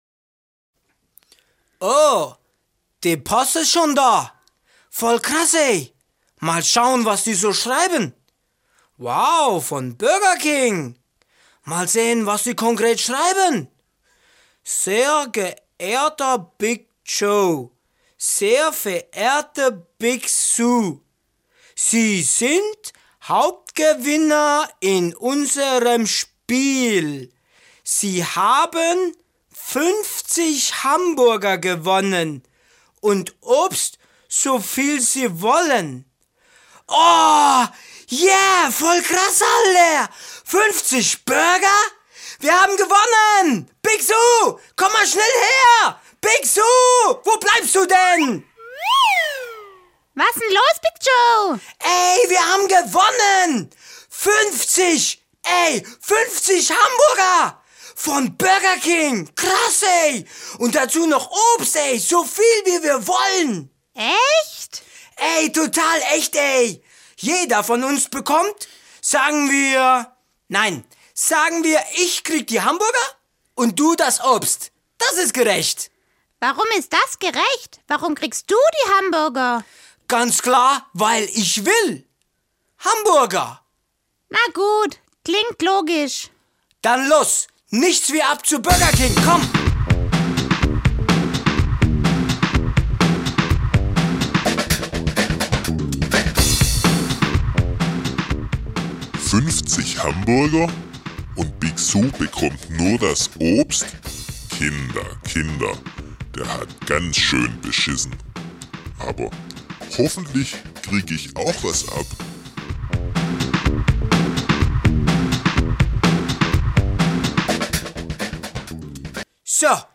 Schöpfung 3_Teilen macht Spaß_Puppentheater_Der Egoist.mp3